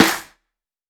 TC SNARE 08.wav